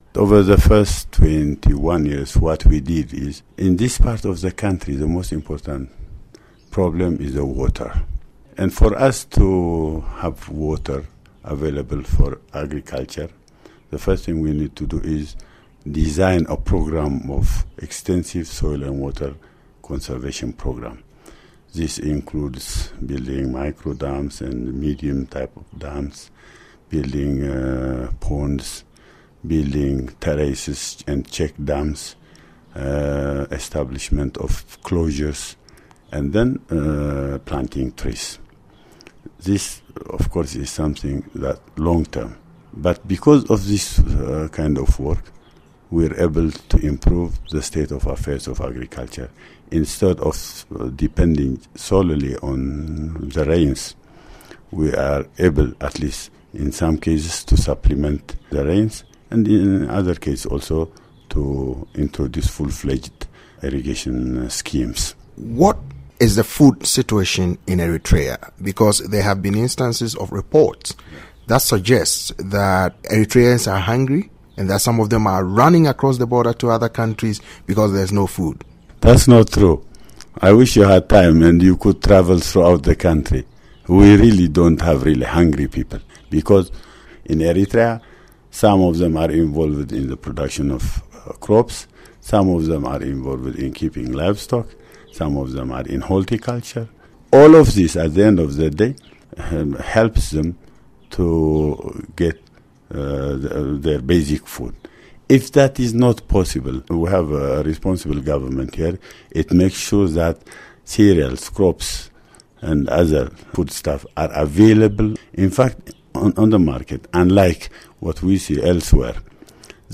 In a recent interview with VOA, agricultural minister Arefaine Berhe repeated the official denials.